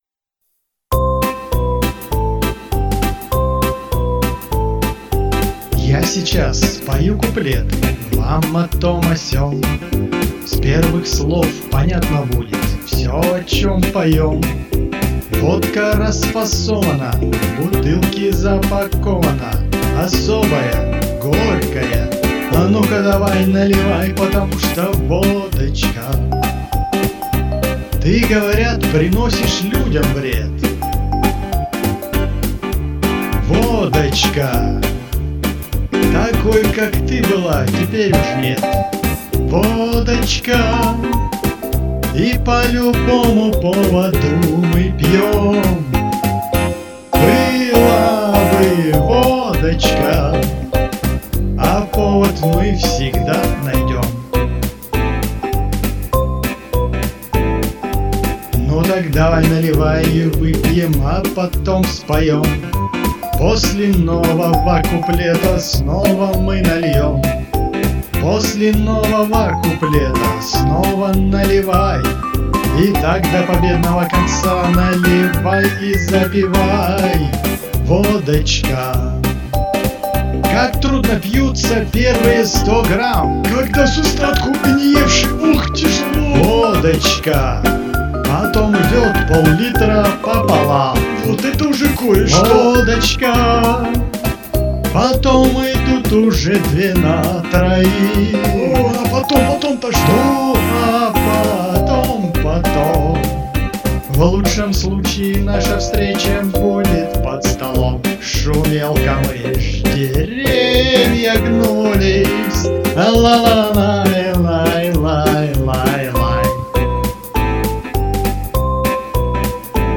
голос ватный